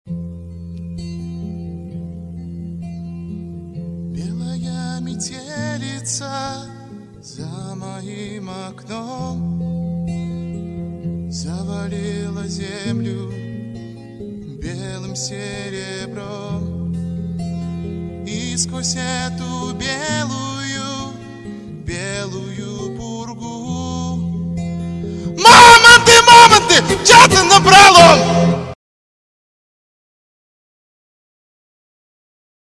Другие рингтоны по запросу: | Теги: Песня, ГРУСТНАЯ